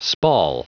Prononciation du mot spall en anglais (fichier audio)
Prononciation du mot : spall